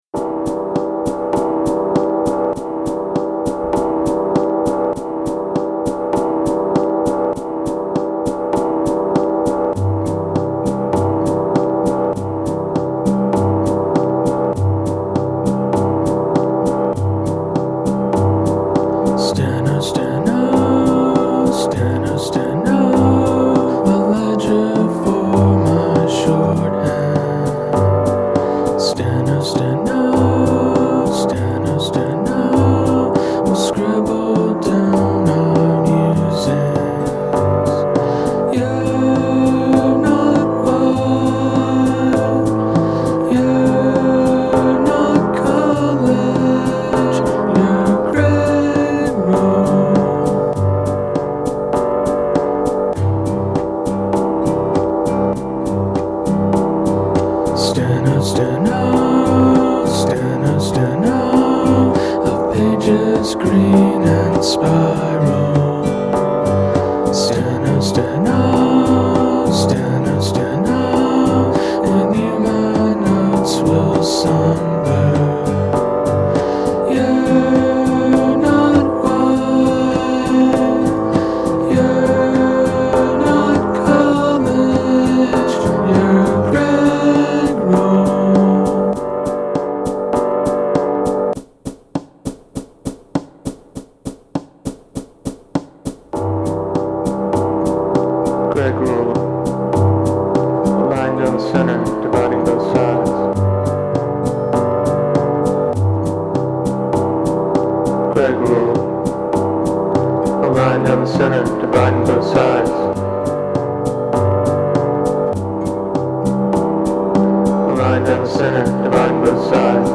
The droning sound in the background is piano recorded on a micro-cassette tape recorder. I chopped up the sounds in a wave editor and matched them up with a simple 4/4 beat from my drum machine (I fiddled with the EQ on the drum track to give it a more muffled sound). For each chord, I paired the original sound with a compressed version of the same sound, one after the other. After stringing it all together, I went back on top with acoustic guitar and vocals. I added the little tape button stop noise at the end for kicks.
nice idea, sweet warm sound, well executed. welcome to the best of hellagems